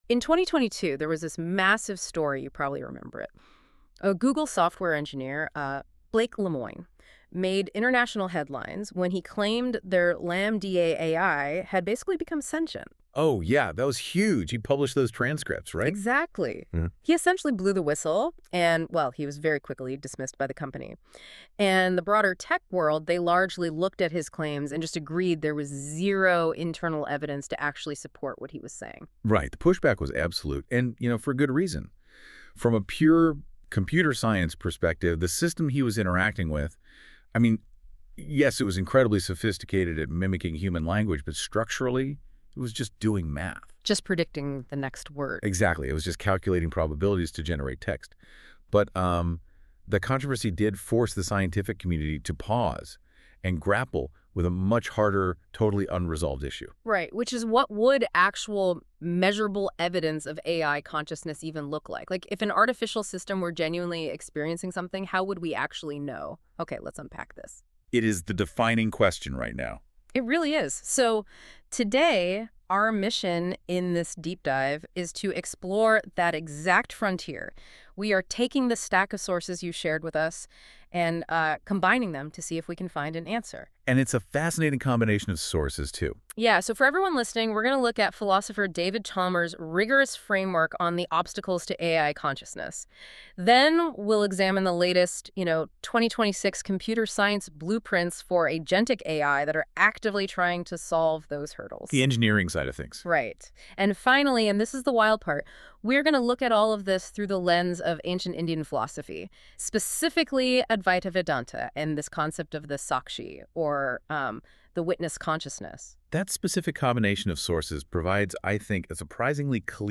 You can hear a Notebook LM Podcast that was created based on the content here.